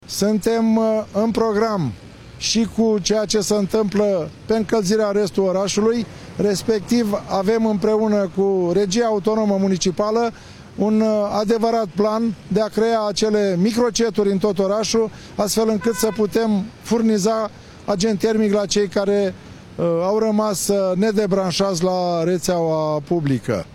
În același timp, continuă procesul de transformare a punctelor termice din cartierele Buzăului în microcentrale, după cum a mai declarat primarul Constantin Toma: